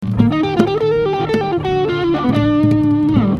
スラー（スライド）・グリッサンド
このスラーU&DはWring That Neck のメインフレーズでも使用されているようであるが、そのメインフレーズを追っていくと唐突な指使いとして小指のスラーダウンを実施しているので下記に紹介しておこう。
スラーさせなくともコピー後の結果音に格別な異質性を感じないため、こうなってくると耳コピでは絶対にスラー使用の実態はわからない。